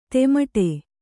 ♪ temaṭe